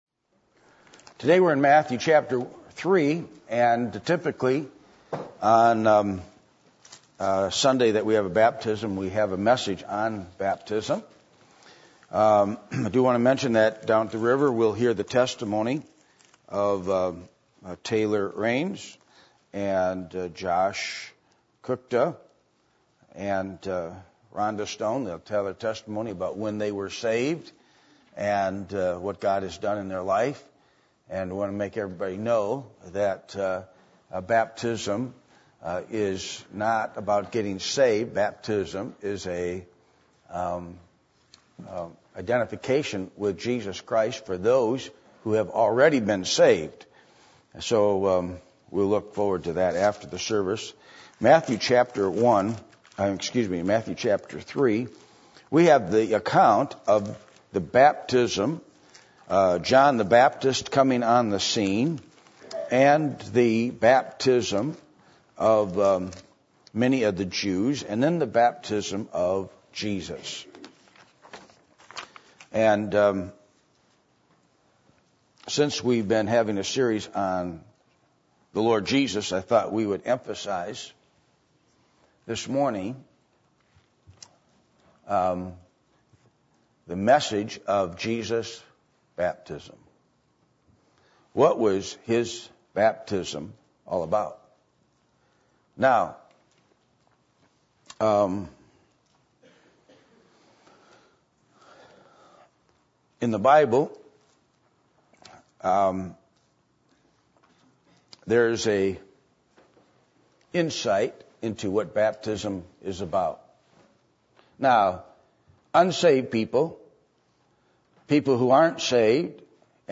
Passage: Matthew 3:1-17 Service Type: Sunday Morning %todo_render% « Music And Jesus When It Is God’s Will